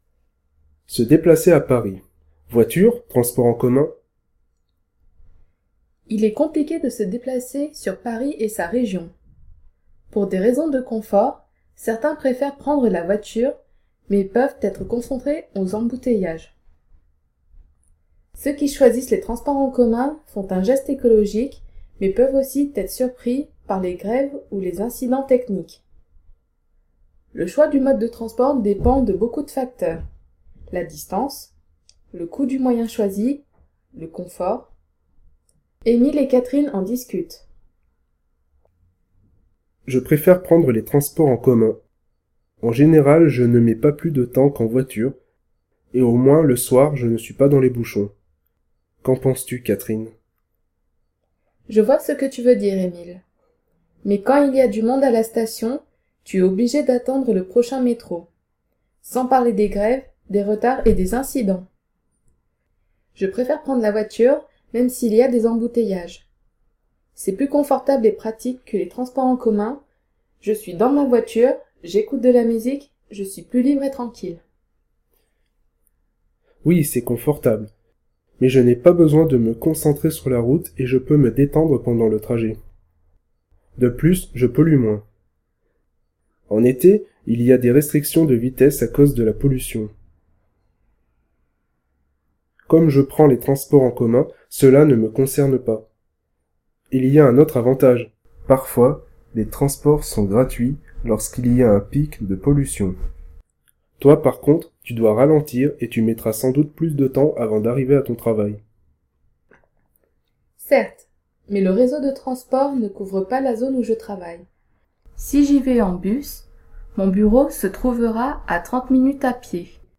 Comprehensive practice for the listening element of the A2 AQA French exam. 18 specially written texts recorded by native speakers, with exam-style questions and answers.